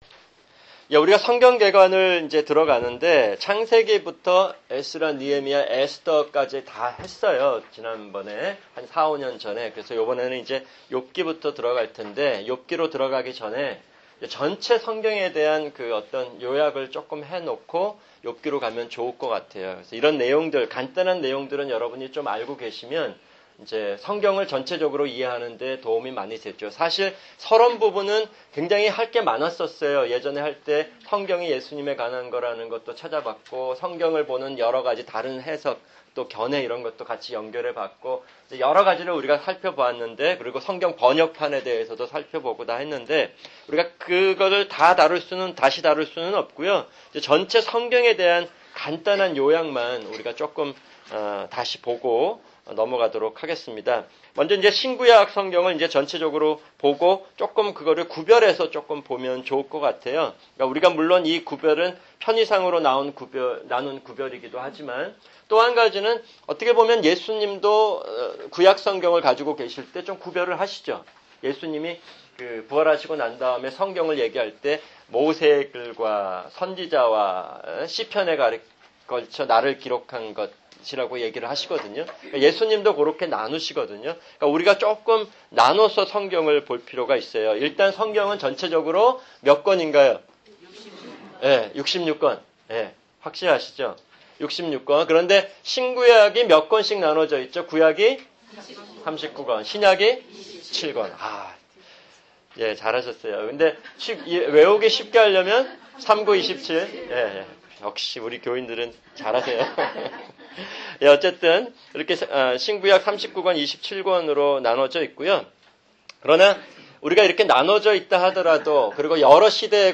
[주일 성경공부] 성경개관-욥기(1)